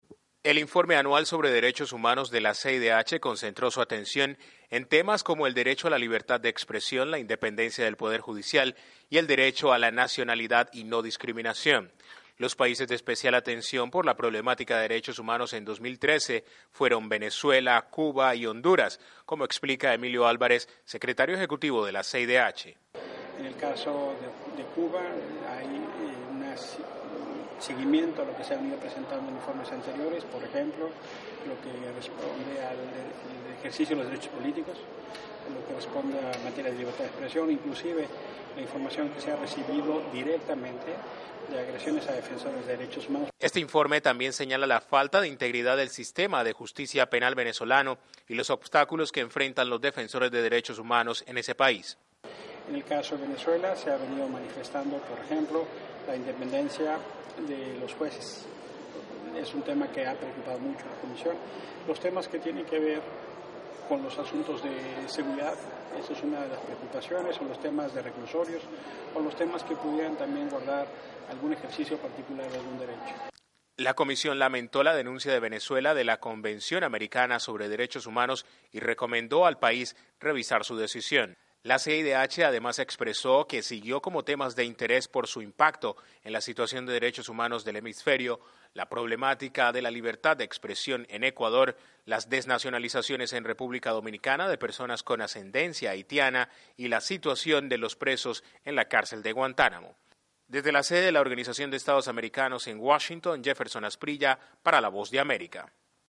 La Comisión Interamericana de Derechos Humanos, CIDH, presentó ante el pleno de la OEA, el reporte anual sobre derechos humanos 2013. Cuba, Venezuela y Honduras figuran como los países con serios problemas de derechos humano. Desde la Voz de America en Washington informa